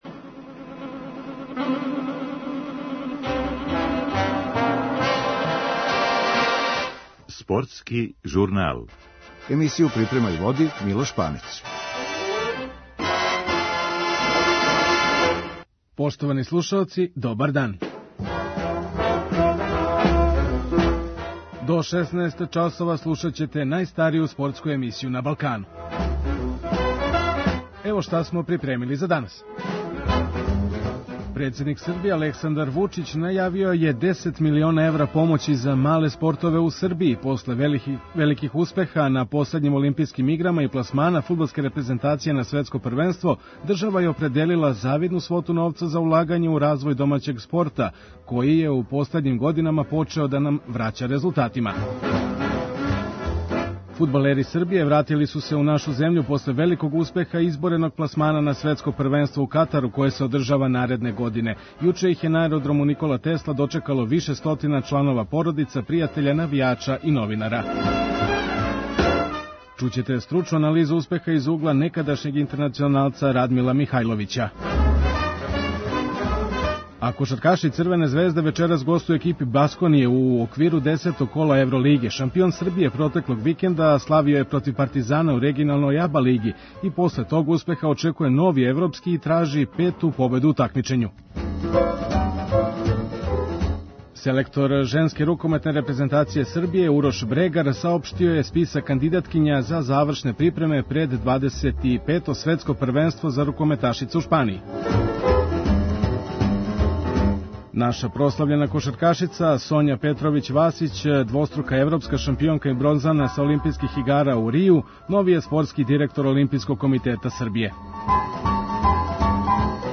Чућете главне актере међу којима је владало добро расположење и слављеничка атмосфера.